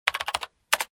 keyboard1.ogg